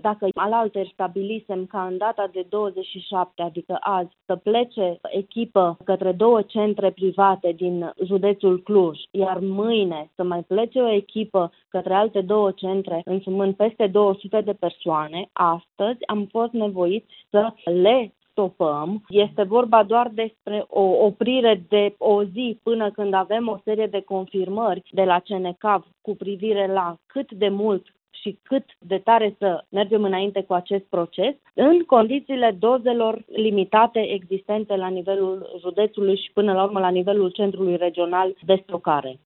Reprezentanții acestora au fost înștiințați deja de suspendarea, temporară, a imunizărilor, potrivit subprefectului Irina Munteanu, pe care o puteți asculta aici: